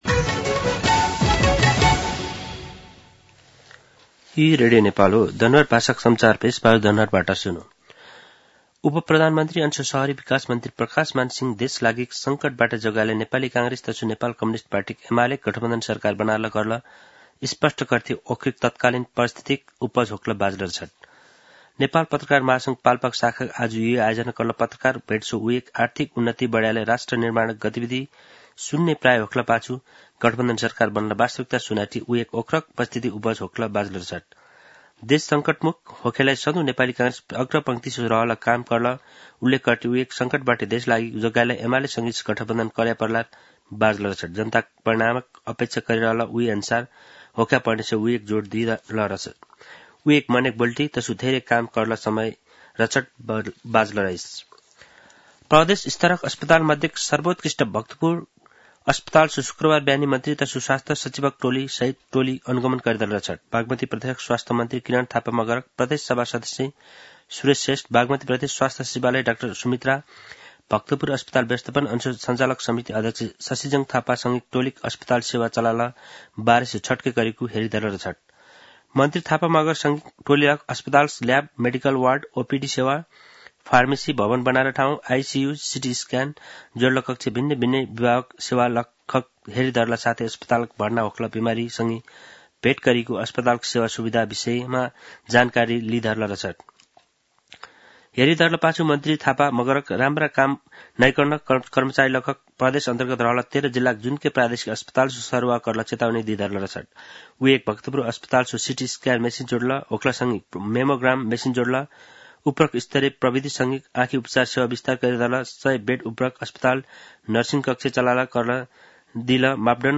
दनुवार भाषामा समाचार : २ चैत , २०८१